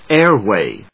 音節áir・wày 発音記号・読み方
/ˈɛˌrwe(米国英語), ˈeˌrweɪ(英国英語)/